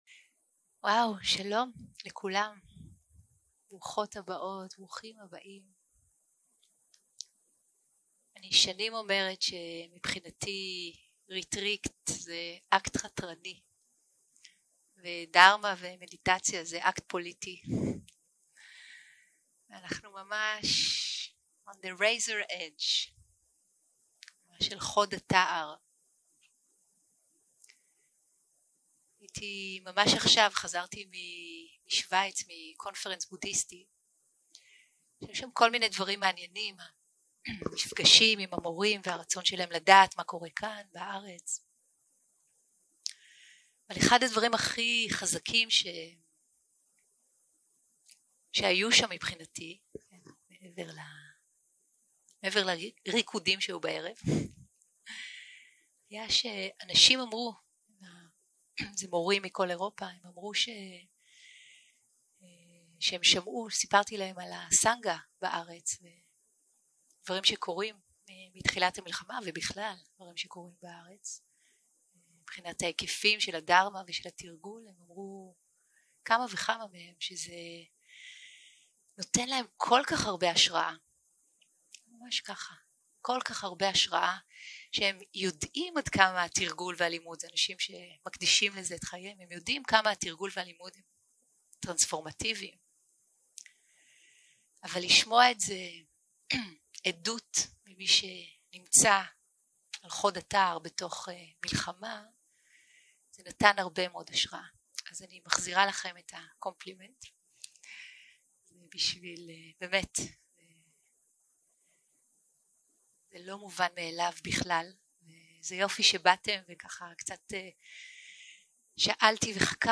Dharma type: Opening talk שפת ההקלטה